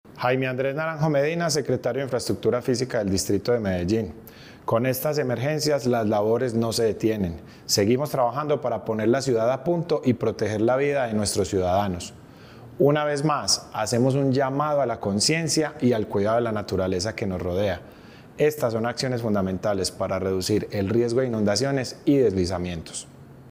Audio Declaraciones del secretario de Infraestructura Física, Jaime Andrés Naranjo Medina
Audio-Declaraciones-del-secretario-de-Infraestructura-Fisica-Jaime-Andres-Naranjo-Medina-1.mp3